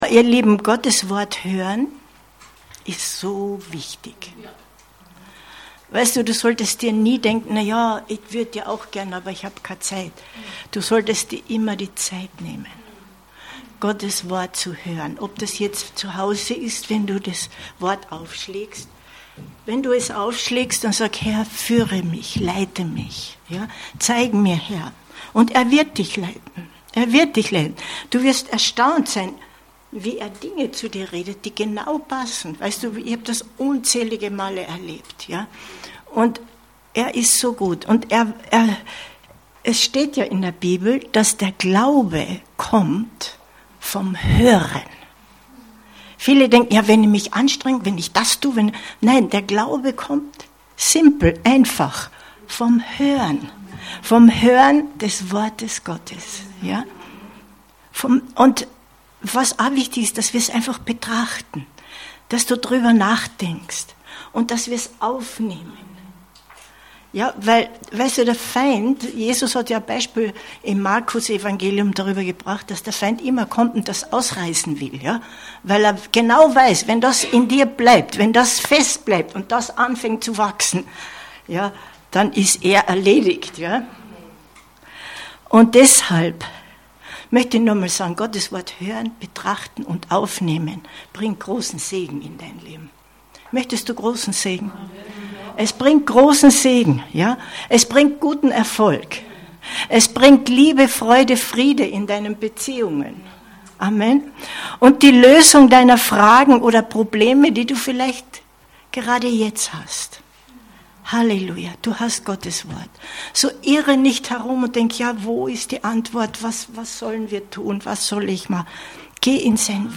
Die verwandelnde Kraft der Dankbarkeit 17.09.2023 Predigt herunterladen